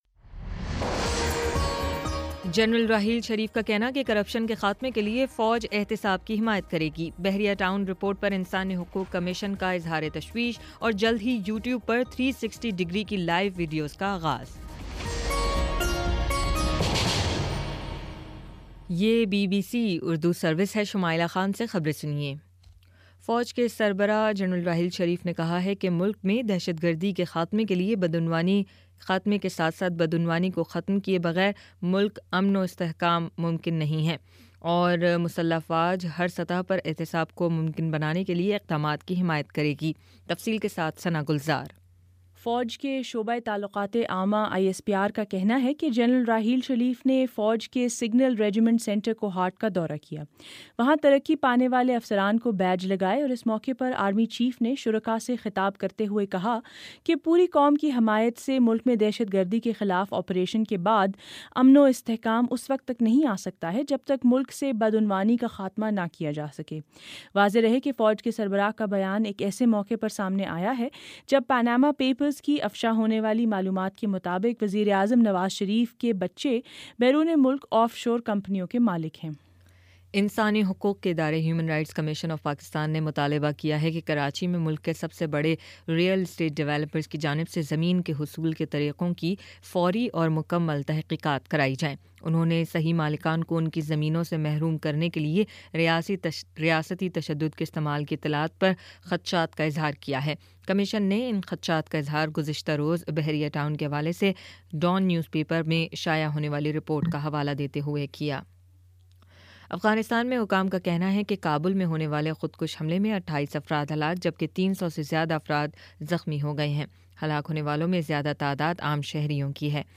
اپریل 19: شام سات بجے کا نیوز بُلیٹن